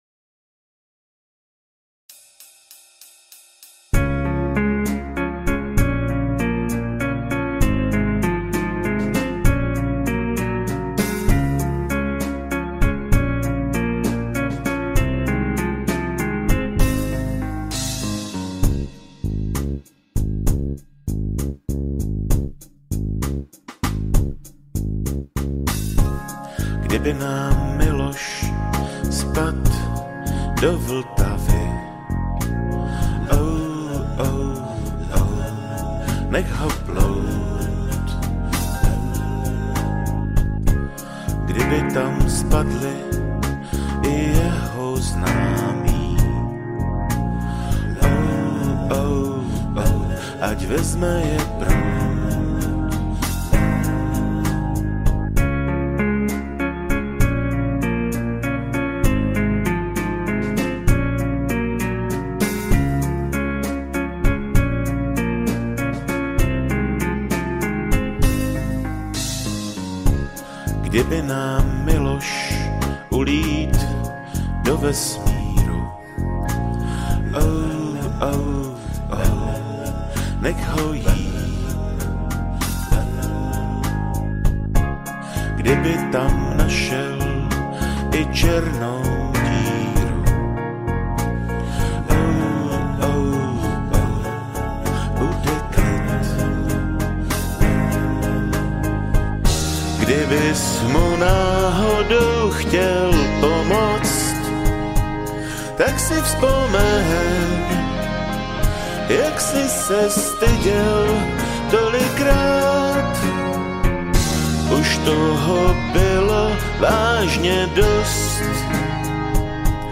Žánr: Pop
písničkáře s notebokem.